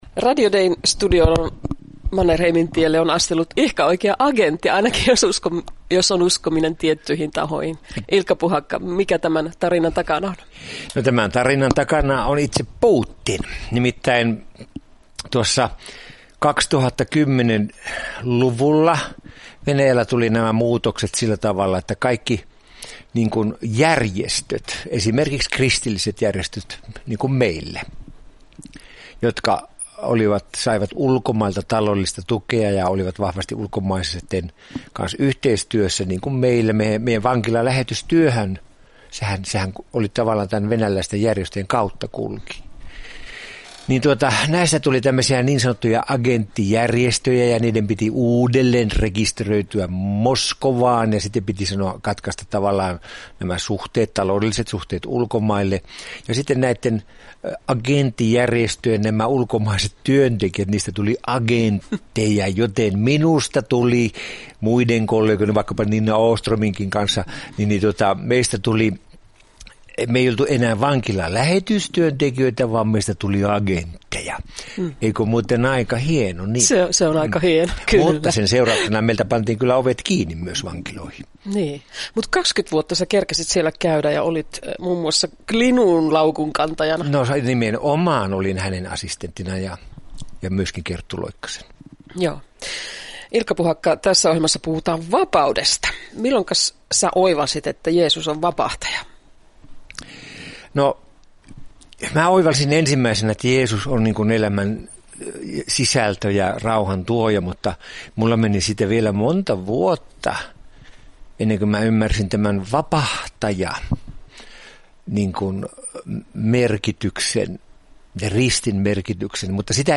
Kutsu vapauteen Radio Deissä la klo 20.03 ja uusinta ke klo 24.